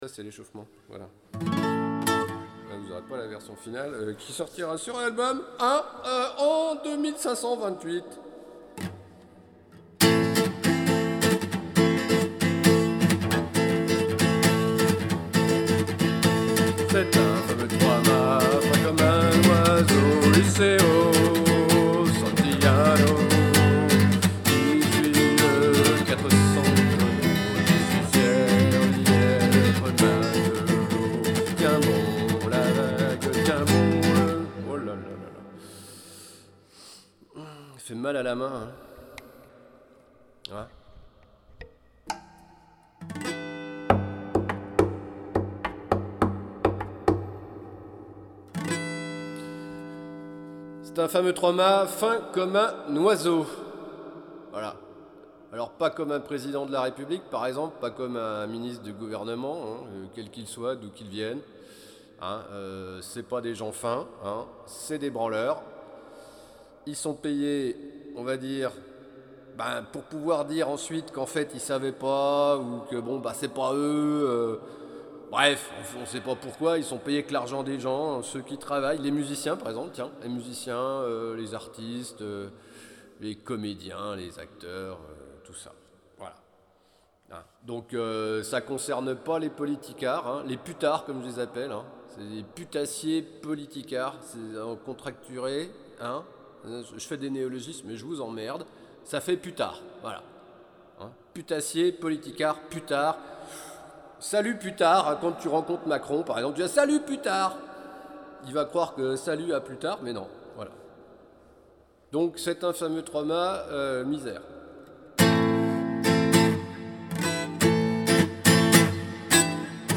En l’église, ça adoucit les erreurs.
Longue introduction, passa lenta et caida rapida.